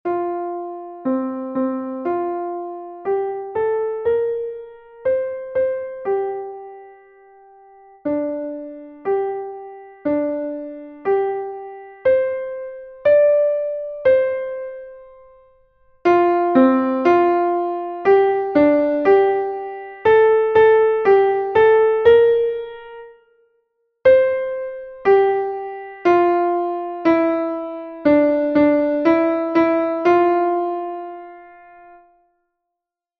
4th interval practice